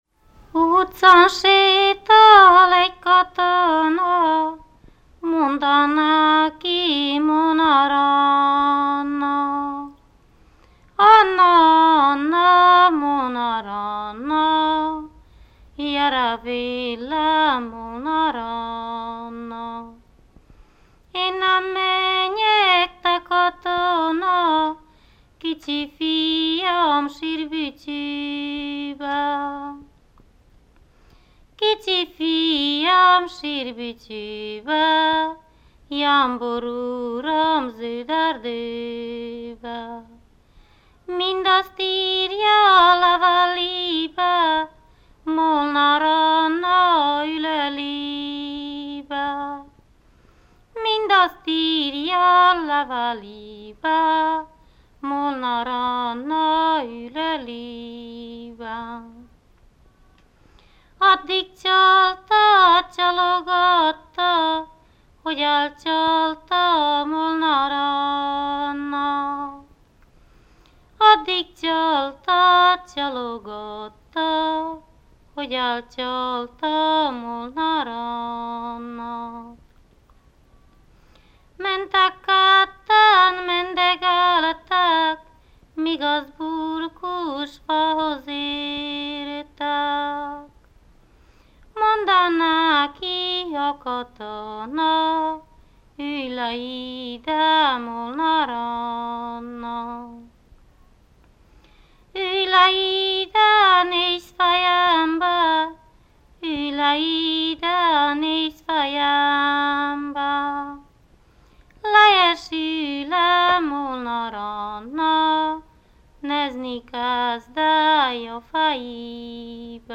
ének
ballada
Moldva (Moldva és Bukovina)